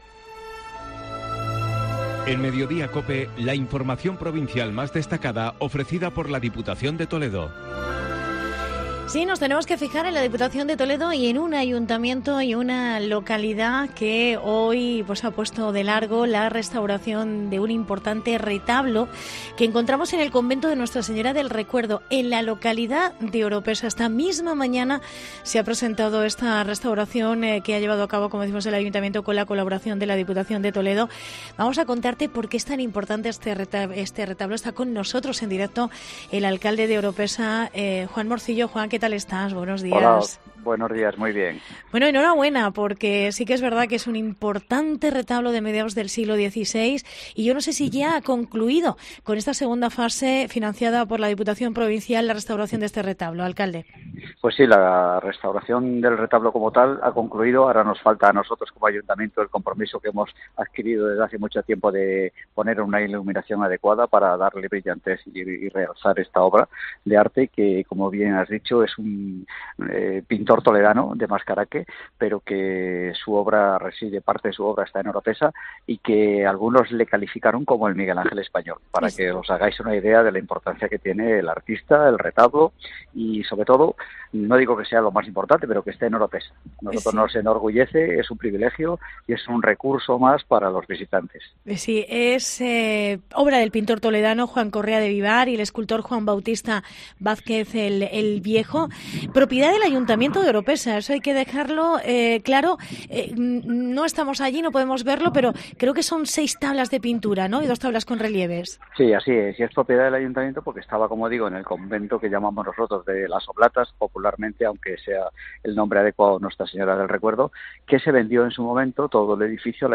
Entrevista a Juan Antonio Morcillo, alcalde de Oropesa